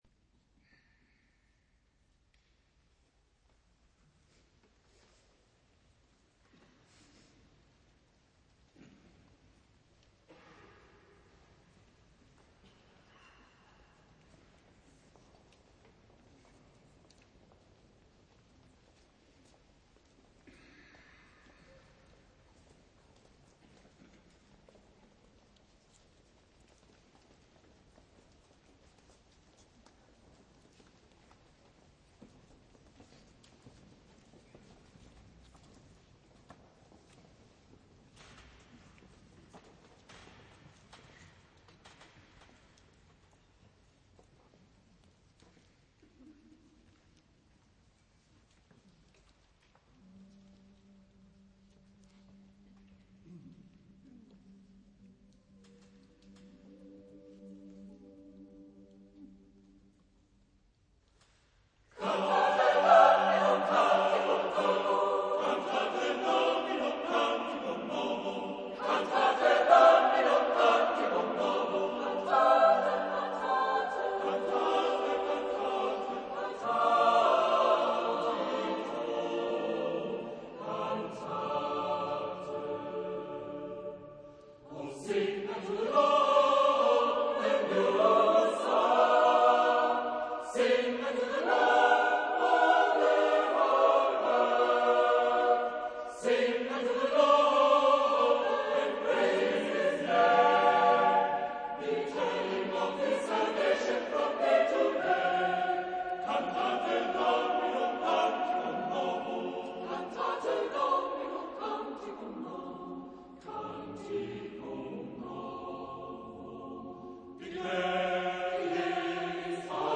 Genre-Style-Forme : Sacré ; Motet ; Psaume
Caractère de la pièce : alerte
Type de choeur : SATB  (4 voix mixtes )
Tonalité : sol majeur